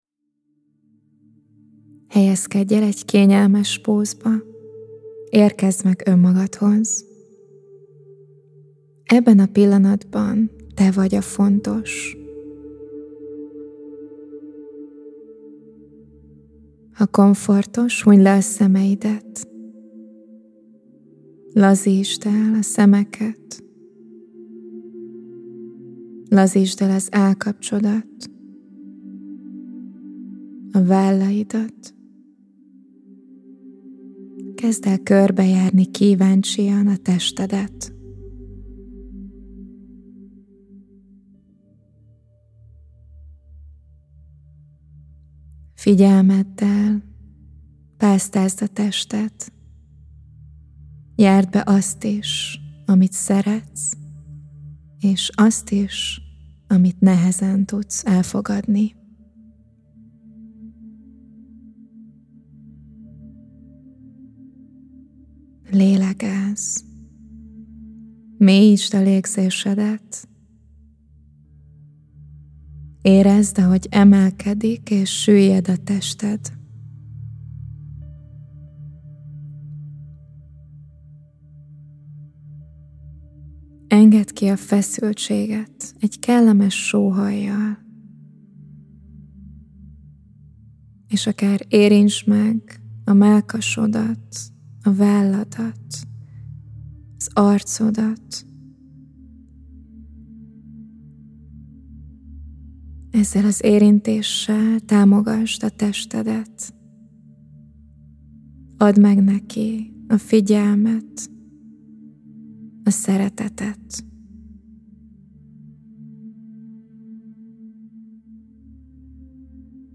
Onbizalom-Noiesseg-Turelmes-vagyok-a-testemmel-meditacio.mp3